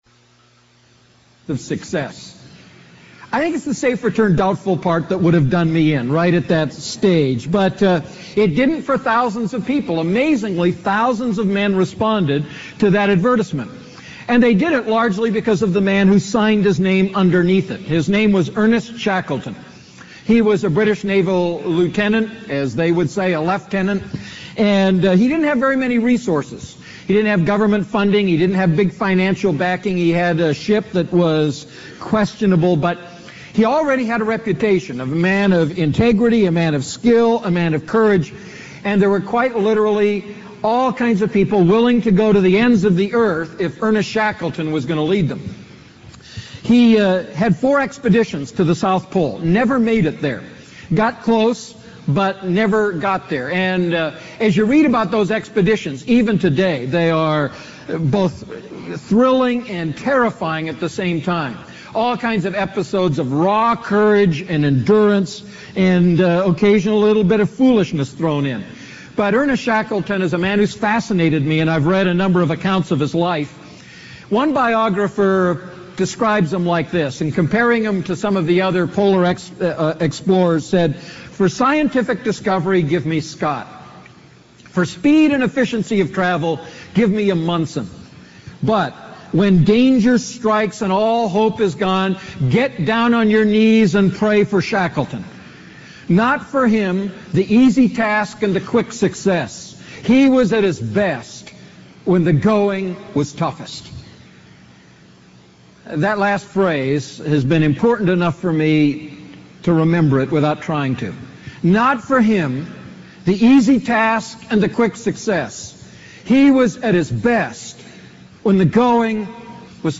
A message from the series "Wise Walking."